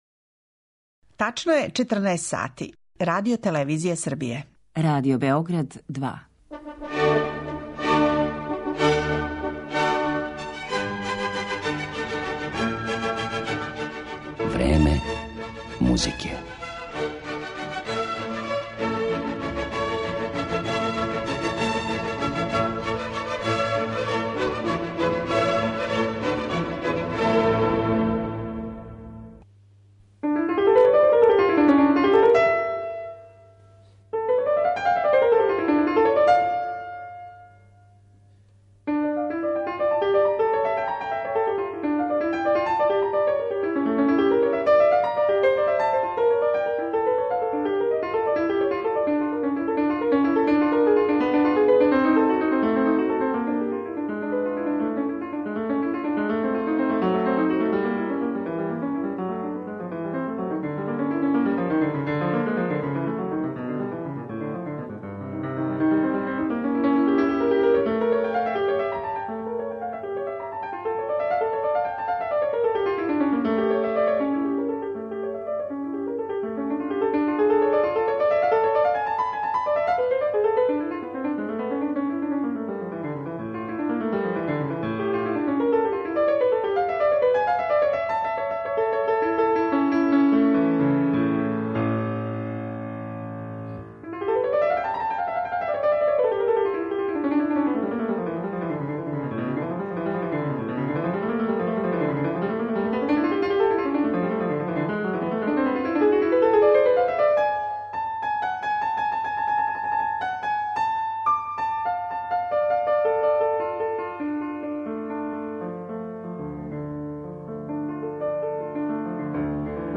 Слушаћемо снимке које је за Радио Београд снимио током 70-их година прошлог века, а о њему ће говорити његов рођак - професор Владета Јанковић.